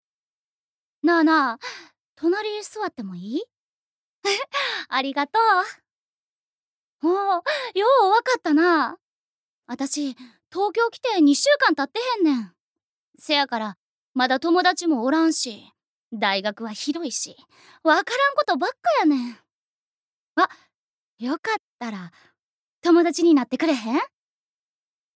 Voice Sample
セリフ４